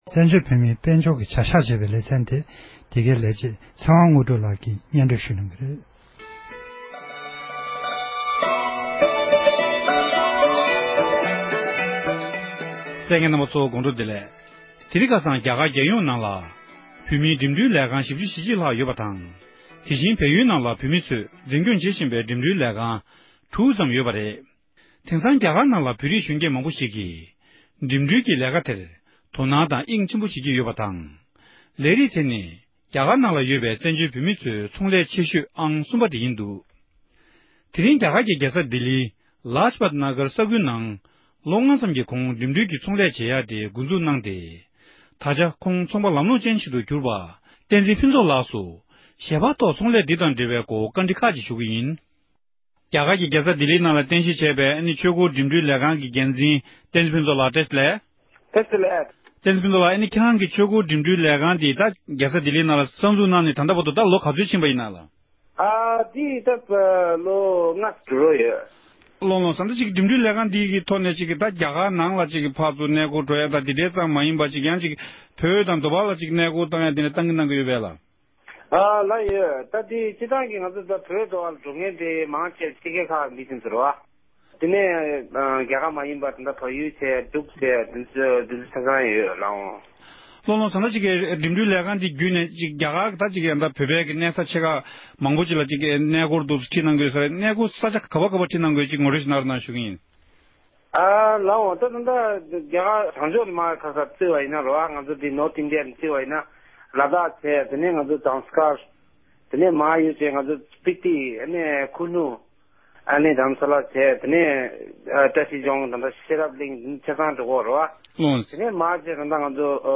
ཚོང་ལས་དེའི་སྐོར་གནས་འདྲི་ཞུས་པར་གསན་རོགས་ཞུ༎